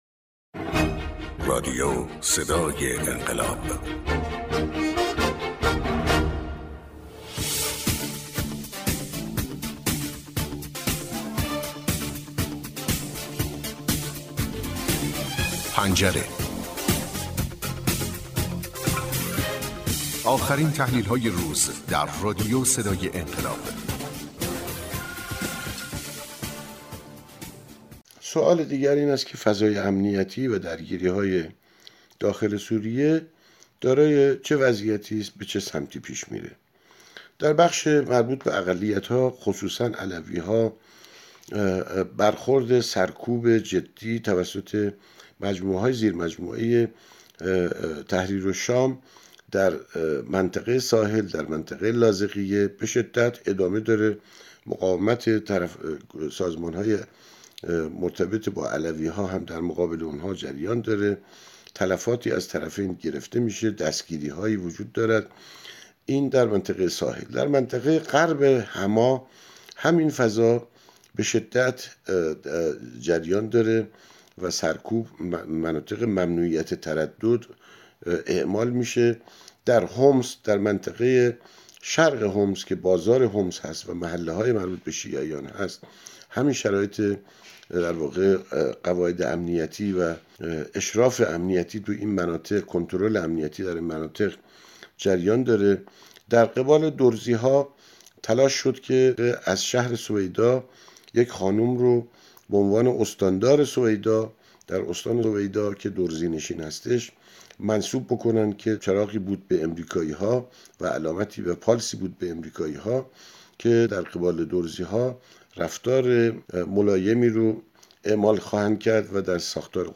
تحلیل گر منطقه و بین الملل